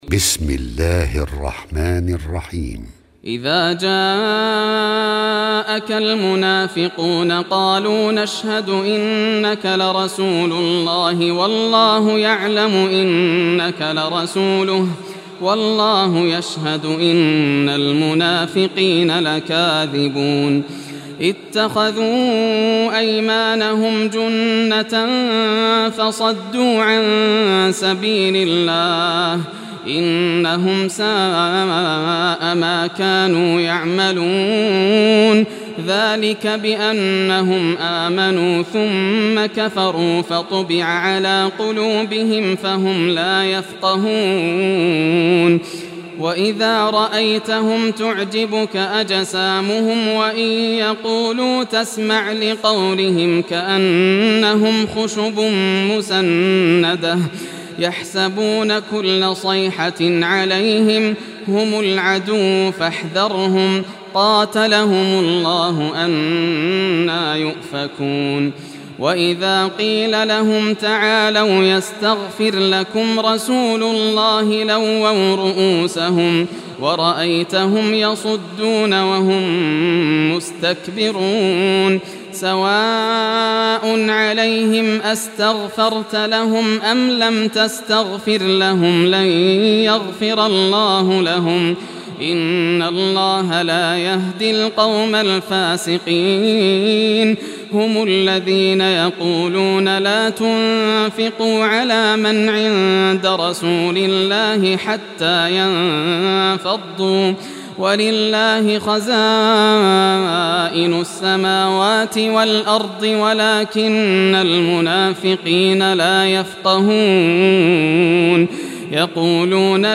Surah Al-Munafiqun Recitation by Yasser al Dosari
Surah Al-Munafiqun, listen or play online mp3 tilawat / recitation in Arabic in the beautiful voice of Sheikh Yasser al Dosari.
63-surah-munafiqun.mp3